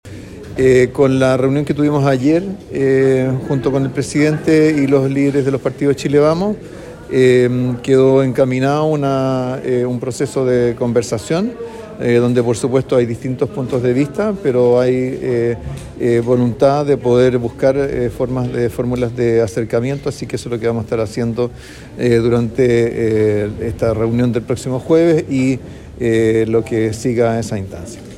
Ministros del Trabajo y Hacienda expusieron en el Teatro Municipal de Osorno
Frente a la próxima reunión con los distintos sectores políticos, el Ministro Marcel señaló que continuarán los diálogos necesarios entregando las propuestas que tiene el ejecutivo para crear un nuevo pacto fiscal.